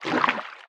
Sfx_creature_brinewing_swim_fast_01.ogg